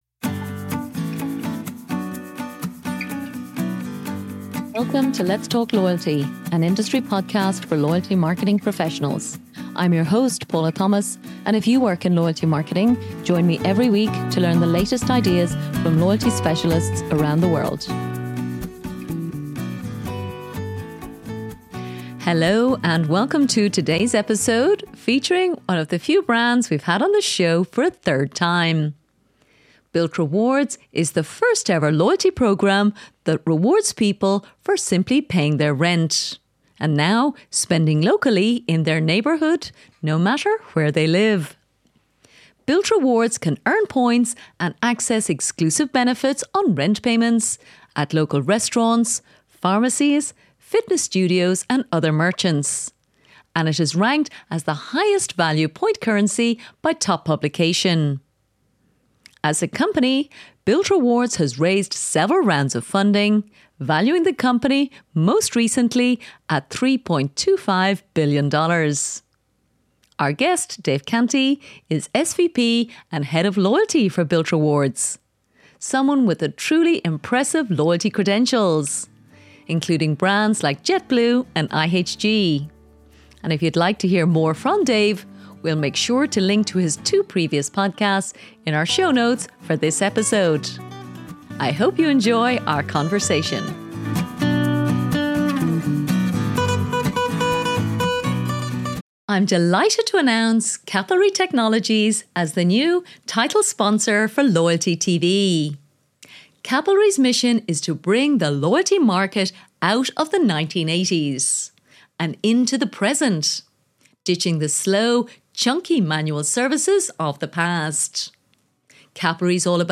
I hope you enjoy our conversation.